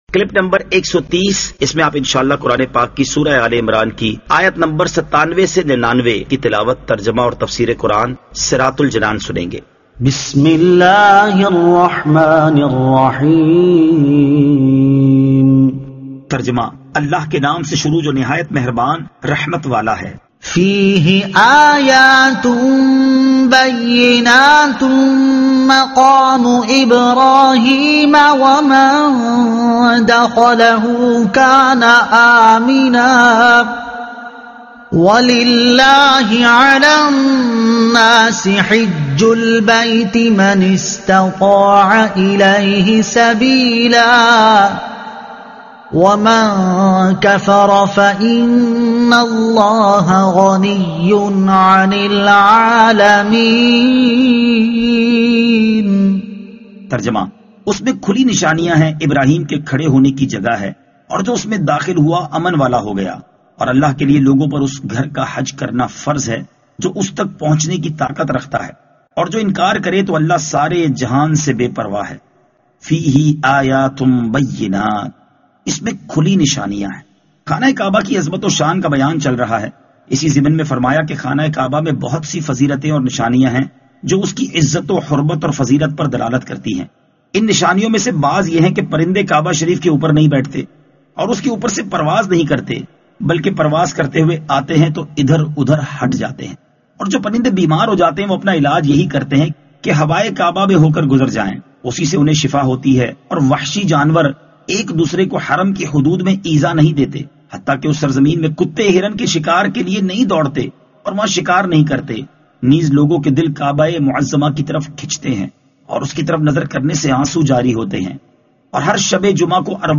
Surah Aal-e-Imran Ayat 97 To 99 Tilawat , Tarjuma , Tafseer